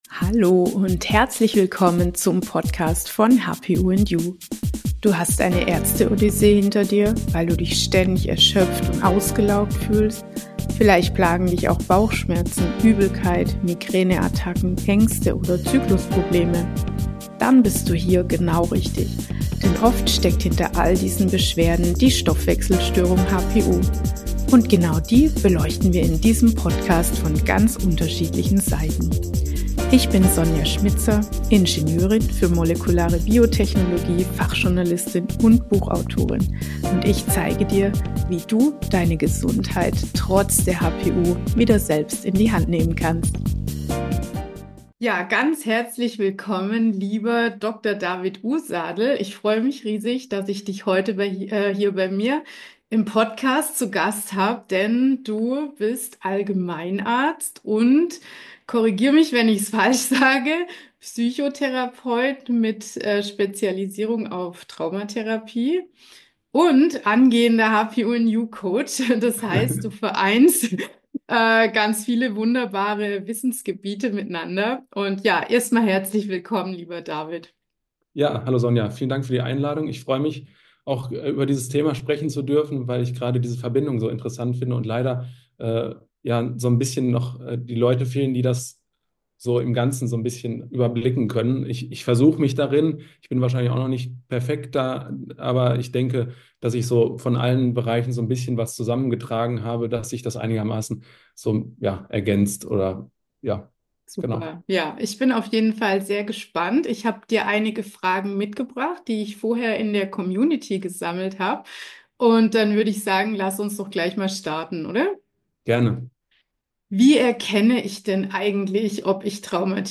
Trauma und HPU - was tun? - Interview